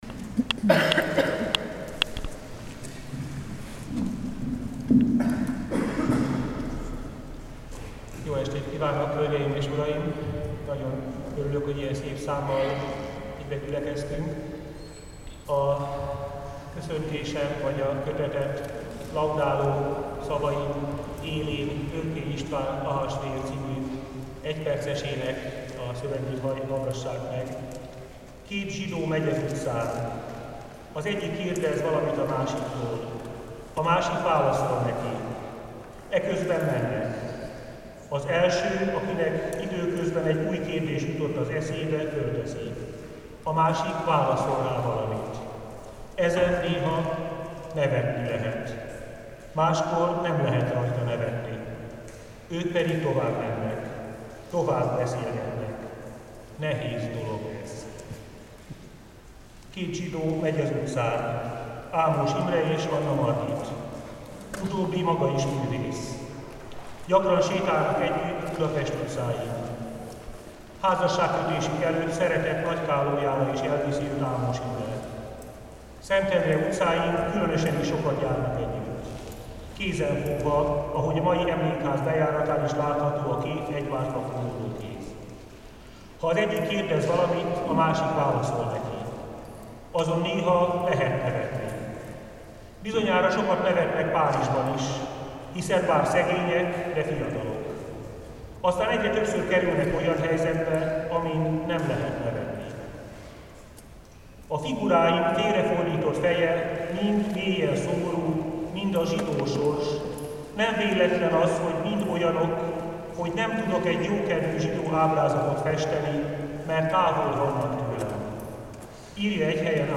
A kötet bemutatóján Fabiny Tamás evangélikus püspök lírai beszédében párhuzamos utakról beszélt.